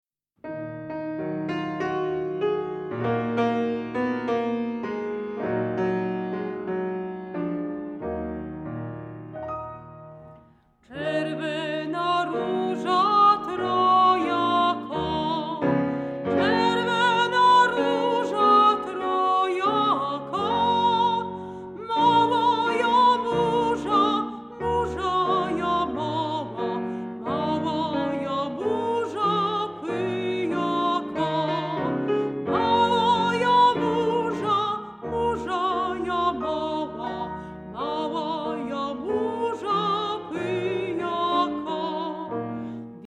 mezzosopran
fortepian